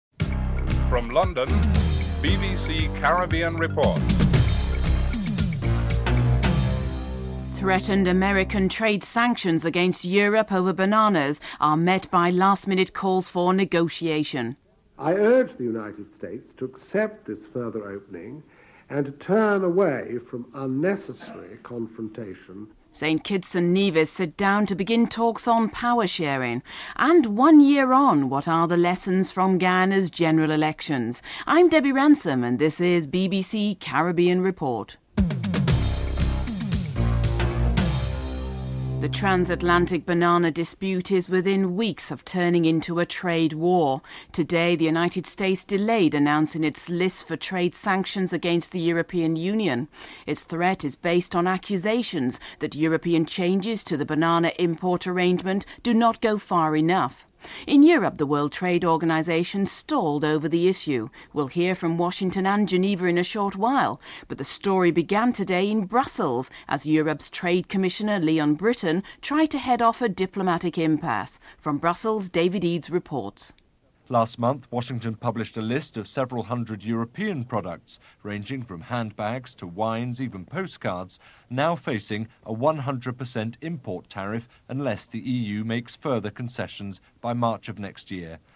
2. Threatened American trade sanctions against Europe over bananas are met by last minute calls for negotiations. Trade Commission Sir Leon Brittan is interviewed.